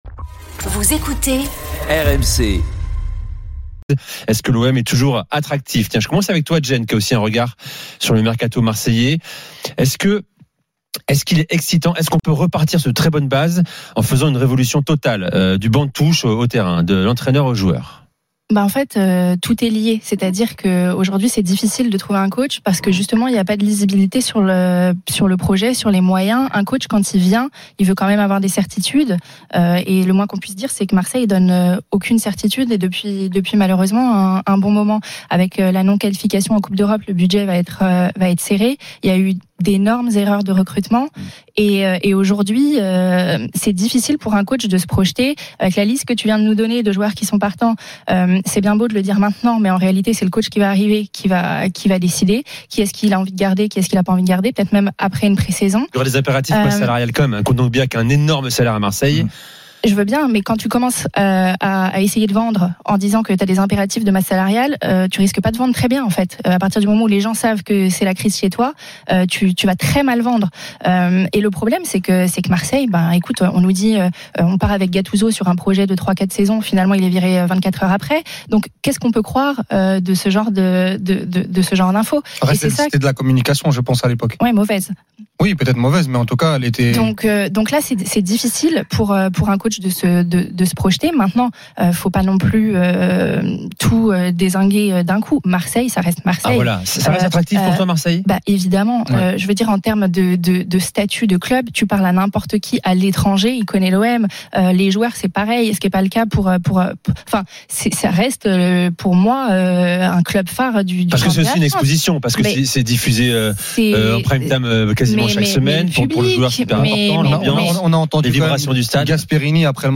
Chaque jour, écoutez le Best-of de l'Afterfoot, sur RMC la radio du Sport !
RMC est une radio généraliste, essentiellement axée sur l'actualité et sur l'interactivité avec les auditeurs, dans un format 100% parlé, inédit en France.